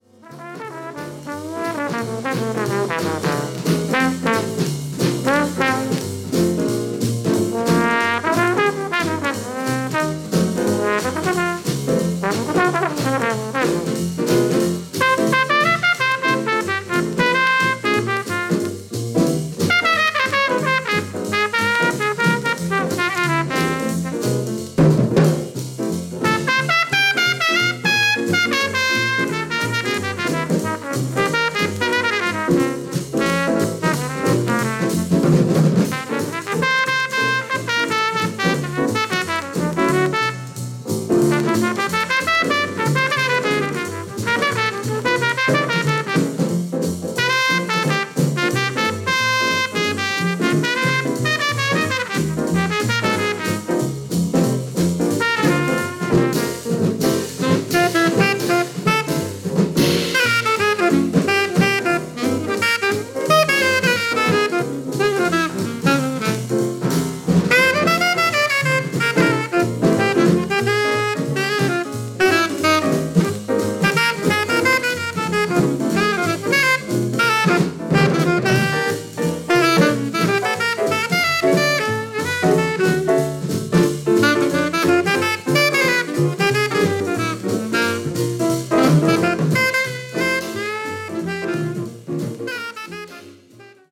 Alto Saxophone